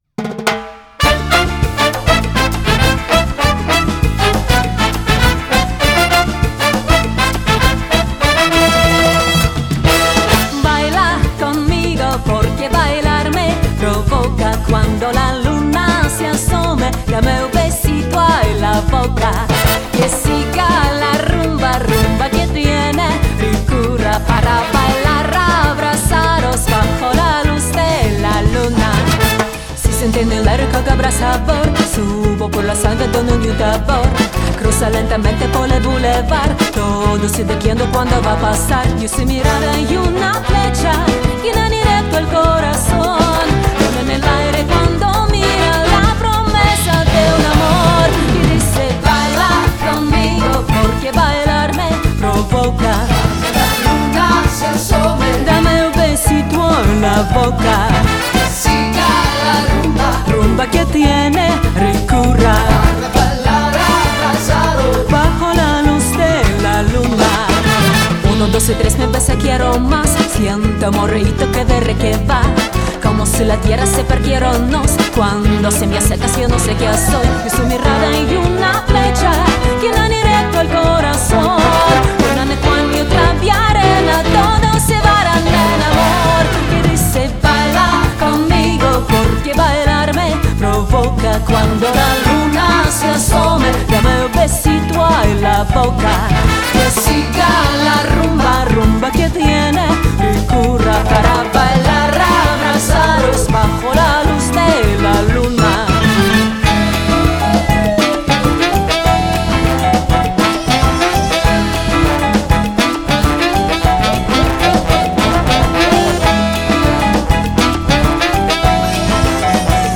Вопрос по сведение записанного по группам биг-бэнда.
Прибрал дилей на трубах. Чуть прибрал в целом ревер на духовых (а вокалу - наоброт чуть добавил). Поднял середину на ф-но (была сильно зарезана, сейчас там тоже осталась "ямка", но поменьше). Еще чуть акцентировал железо на барабанах (на оверхедах ВЧ атаки были немного поддавлены - убрал эту обработку).